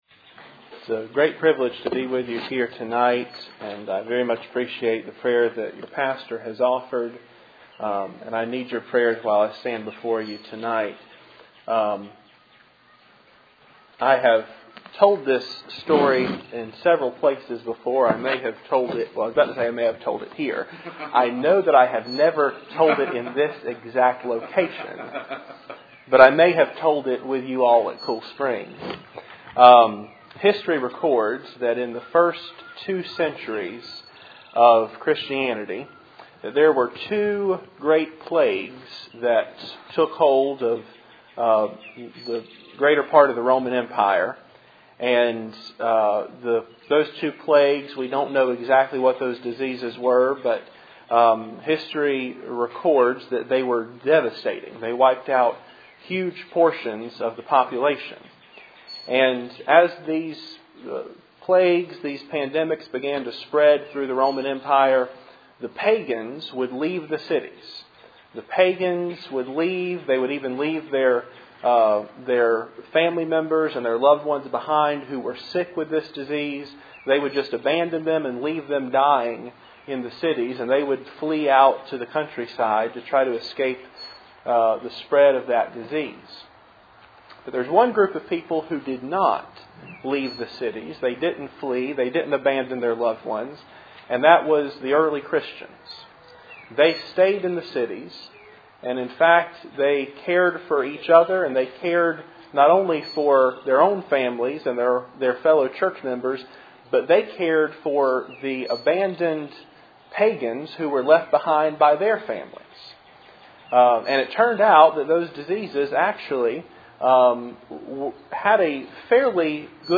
Cool Springs PBC Sunday Evening